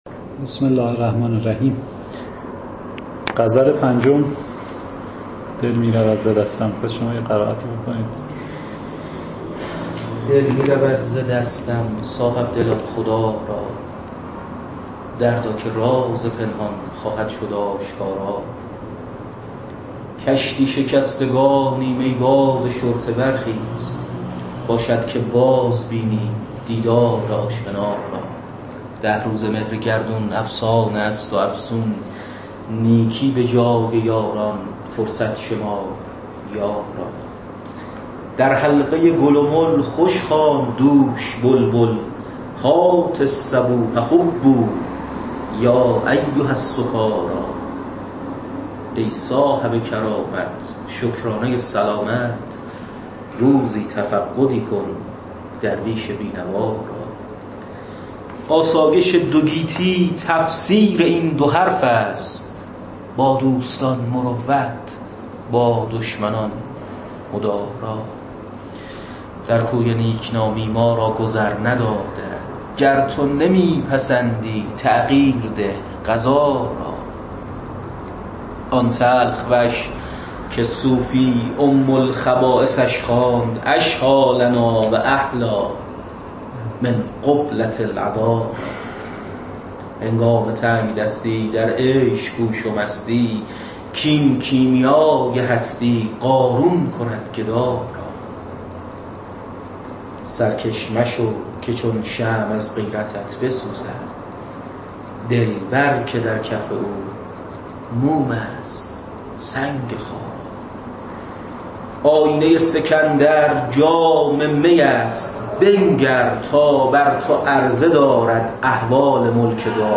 سلسله جلسات حافظ خوانی
برخی از جلسات حافظ خوانی در کتابخانه امیرکبیر